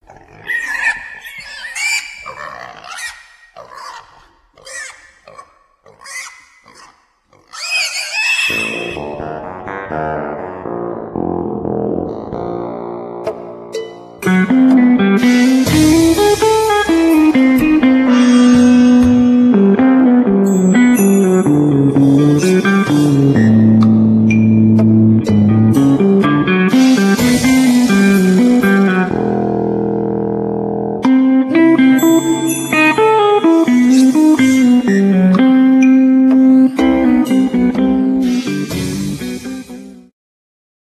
skrzypce
saksofon tenorowy, klarnet
gitara akustyczna i elektryczna
bębny, perkusja
gitara basowa i elektryczna